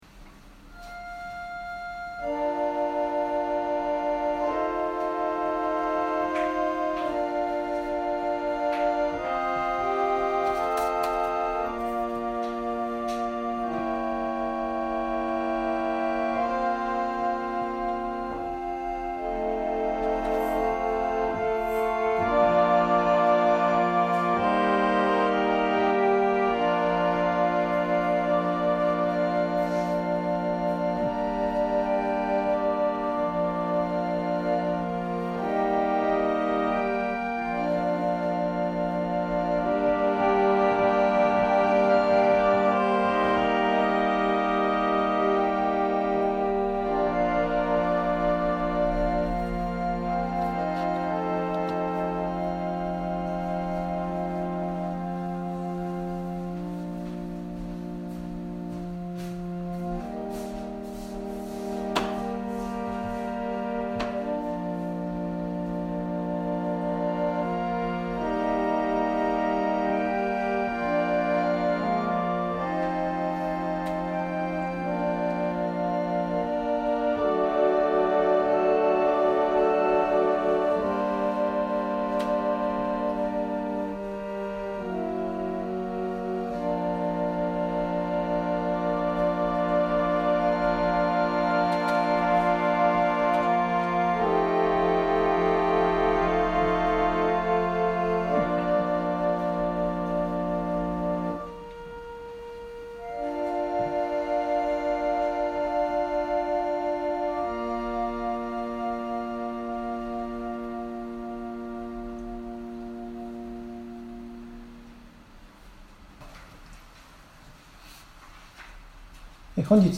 説教アーカイブ。